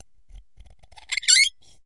玻璃 陶瓷 " 陶瓷冰淇淋碗金属勺子在碗内发出刺耳的声音 03
描述：用金属勺刮擦陶瓷冰淇淋碗的内部。 用Tascam DR40录制。
Tag: 刮下 金属勺 尖叫 刮去 勺子 尖叫 陶瓷 金属